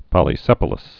(pŏlē-sĕpə-ləs)